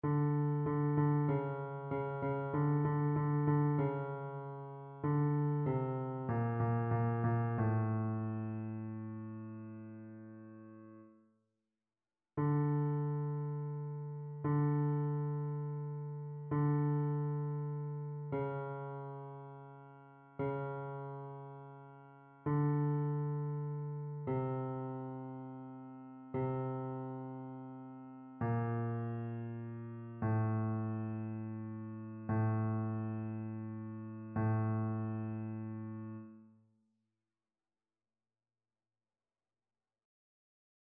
Basse
annee-abc-fetes-et-solennites-assomption-de-la-vierge-marie-psaume-131-basse.mp3